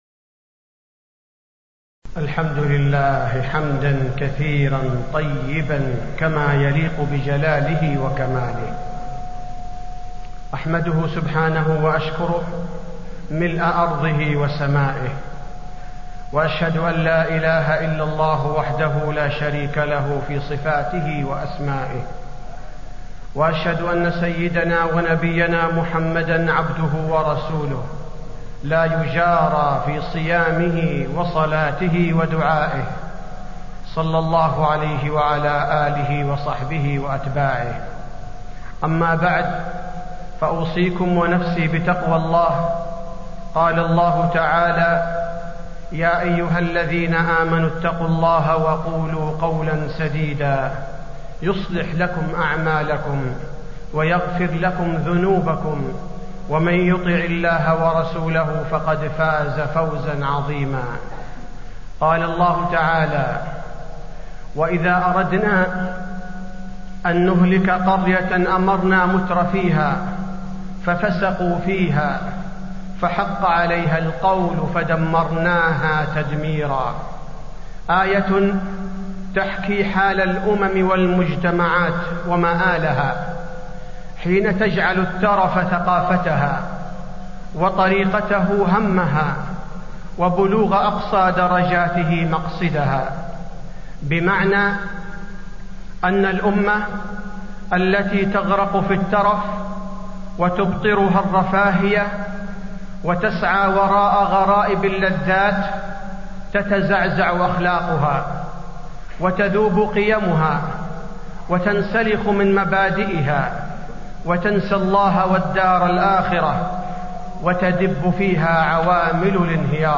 تاريخ النشر ١٦ جمادى الآخرة ١٤٣٤ هـ المكان: المسجد النبوي الشيخ: فضيلة الشيخ عبدالباري الثبيتي فضيلة الشيخ عبدالباري الثبيتي التحذير من الترف